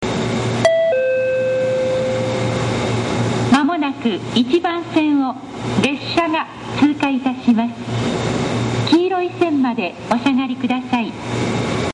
kaihinmakuhari1sekkin1.mp3